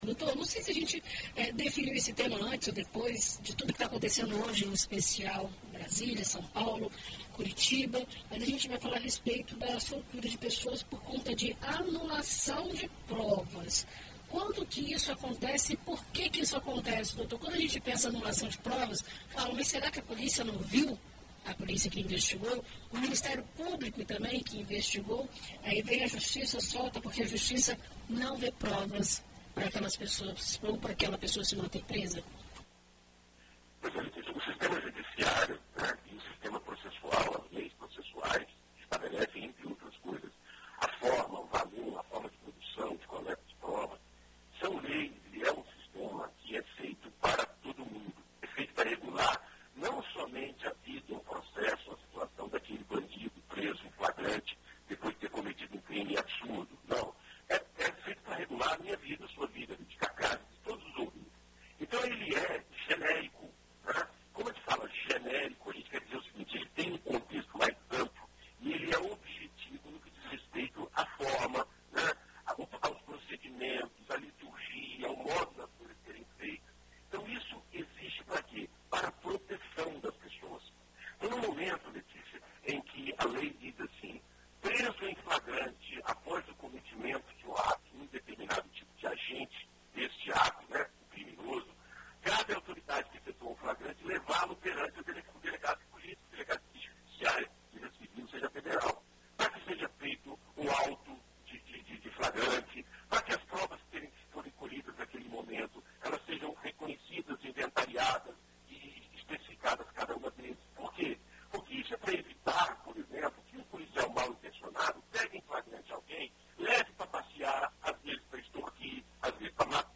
Na coluna desta quarta-feira (22), na BandNews FM Espírito Santo,